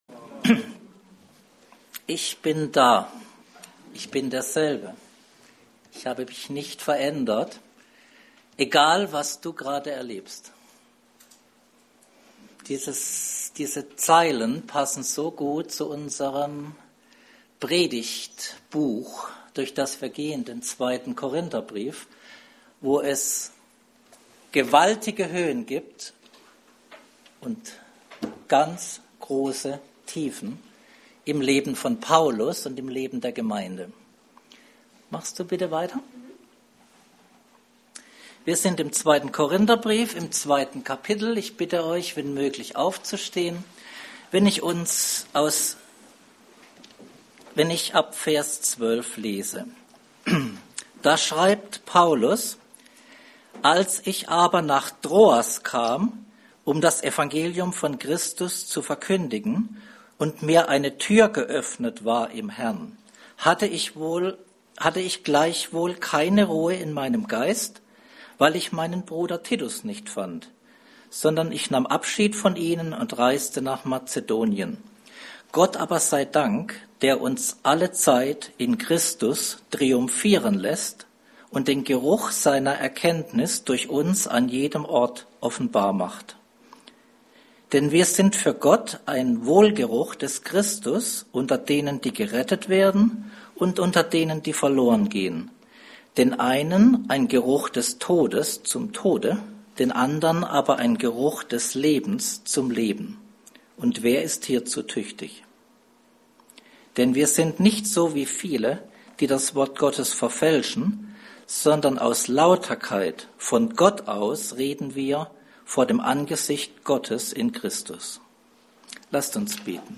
Passage: 2. Korinther 2, 12-17 Dienstart: Predigt